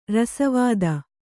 ♪ rasa vāda